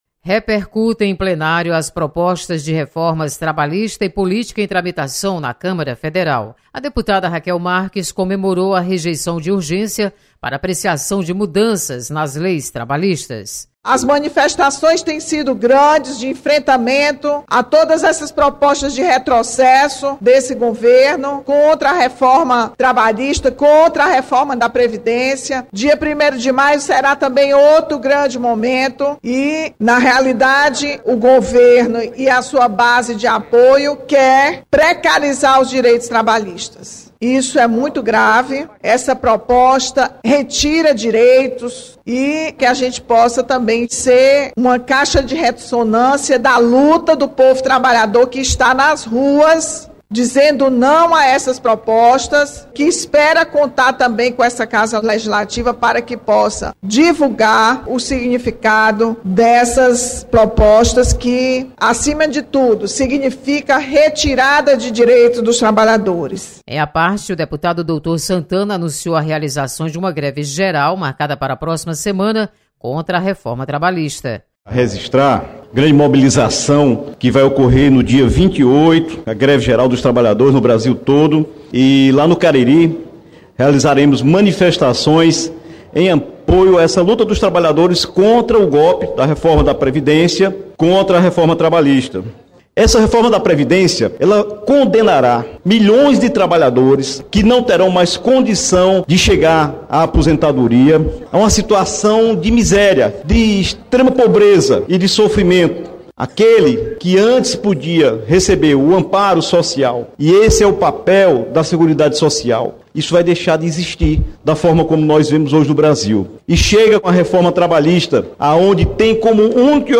Deputados comentam sobre rejeição de urgência na tramitação da reforma previdenciária.